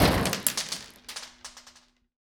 Death.aif